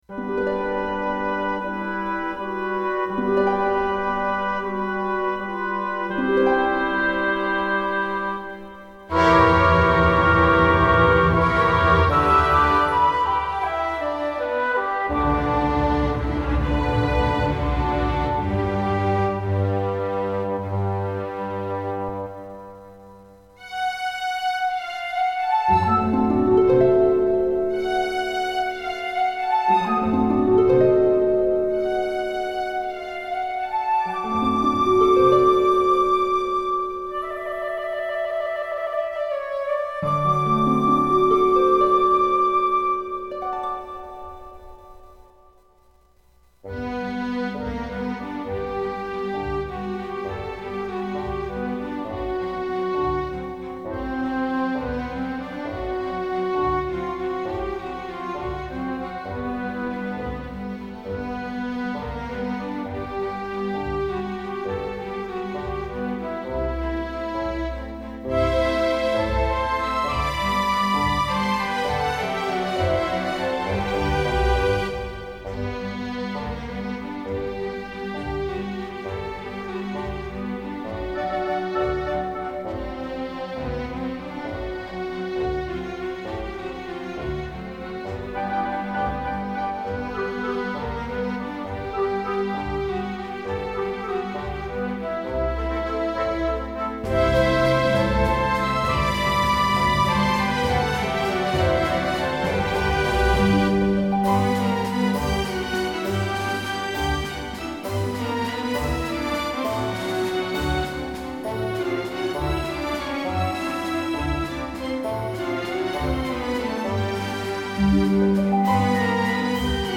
ピアノ楽譜から管弦楽への編曲です。
ウインナワルツ風なモチーフが連なる形式で創られ 心地よい流れるようなメロディが印象的です。